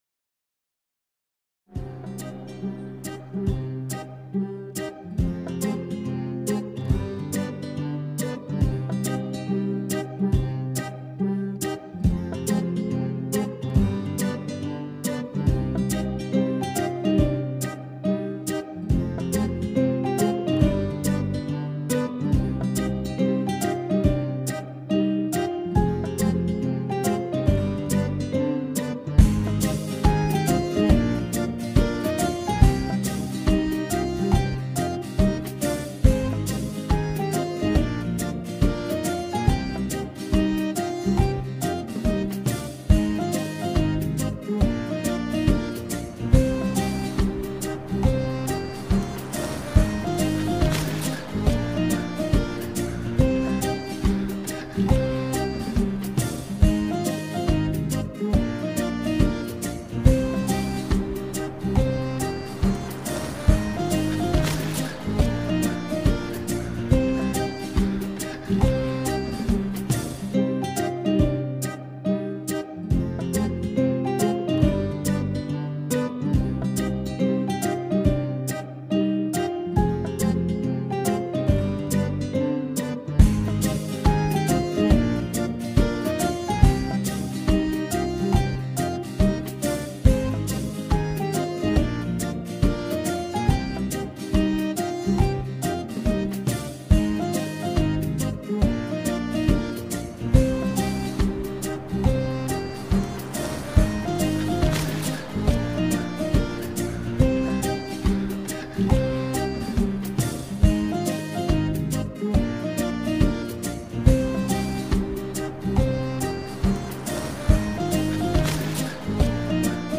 tema dizi müziği, mutlu huzurlu rahatlatıcı fon müzik.